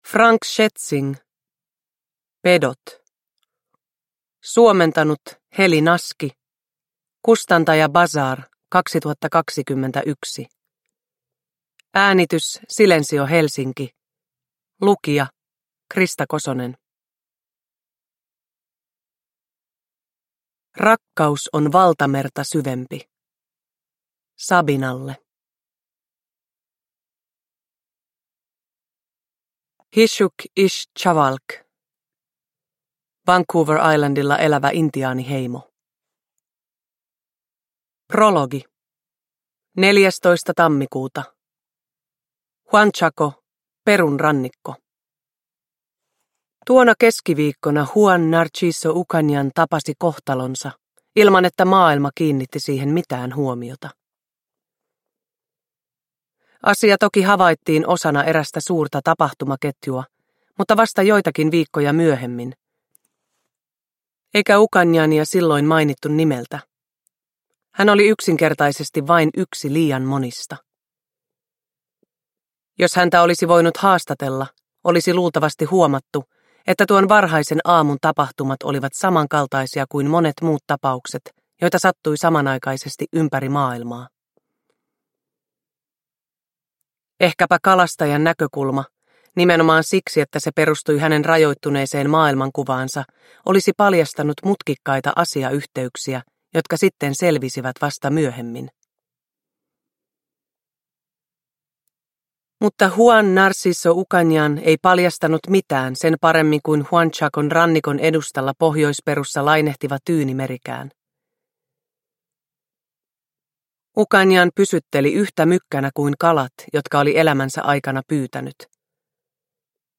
Pedot (ljudbok) av Frank Schätzing